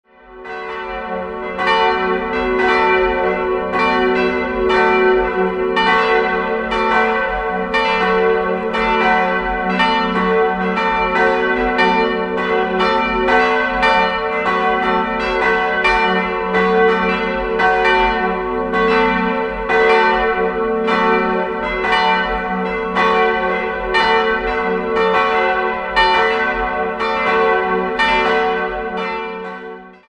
Weil die Kirche für die wachsende Bevölkerungszahl zu klein wurde, errichtete man 1977/78 direkt daneben einen neuen Kirchenraum samt Pfarrzentrum. 3-stimmiges TeDeum-Geläute: fis'-a'-h' Die drei Glocken goss Friedrich Wilhelm Schilling im Jahr 1953. Sie wiegen 610, 355 und 244 kg und erklingen exakt in den Tönen fis'+5, a'+6 und h'+5.